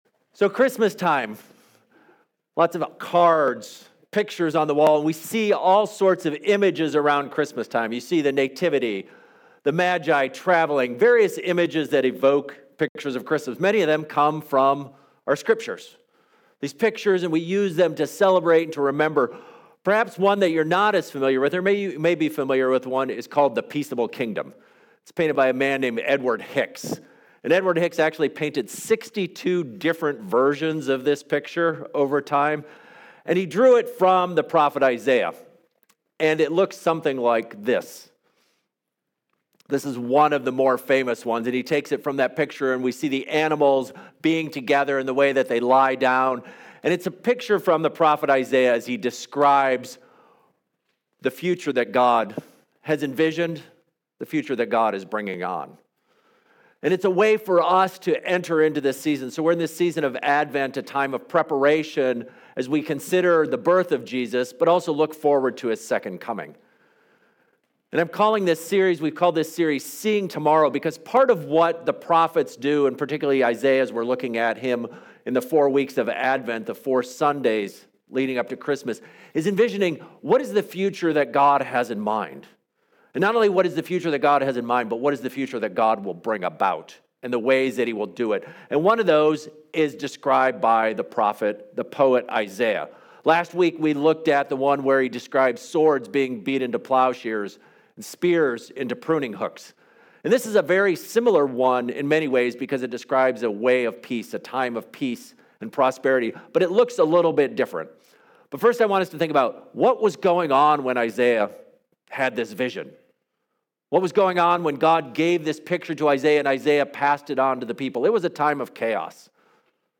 The sermon presents two interpretations of Isaiah's peaceable kingdom—literal animals or metaphorical predators and prey among people.